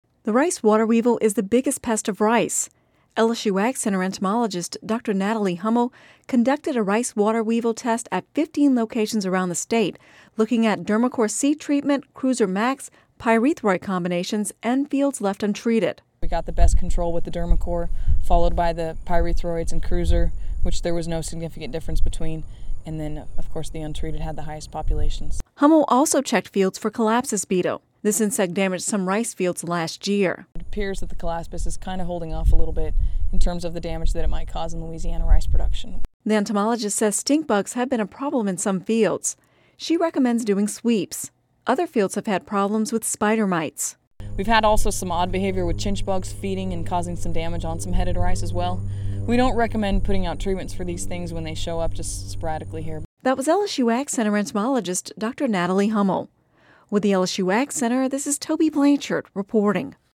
(Radio News 7/19/10) The rice water weevil is the biggest pest of rice.